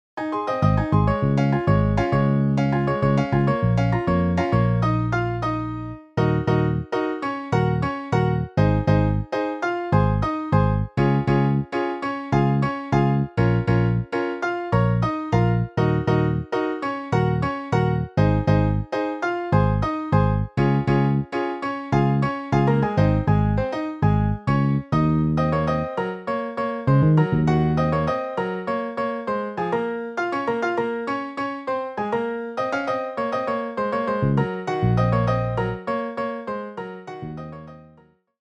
Saxo Alto, Trompeta, Trombón, Piano, Bajo